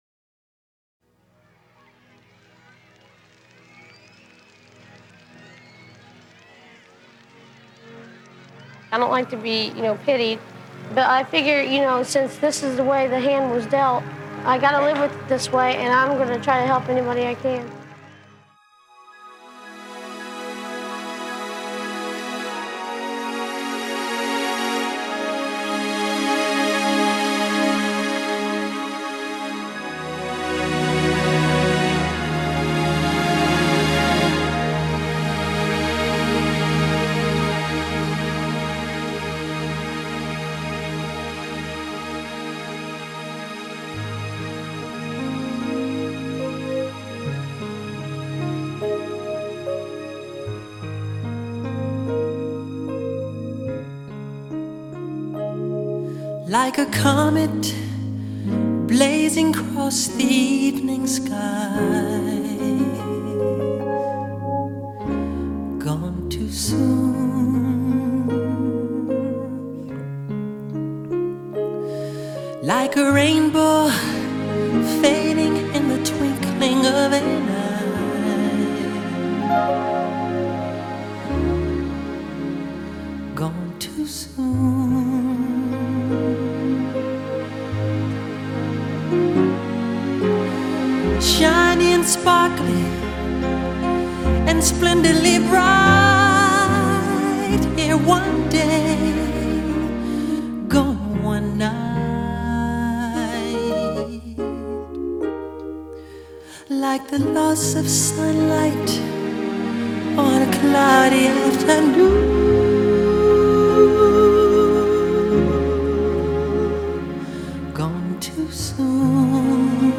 pop Music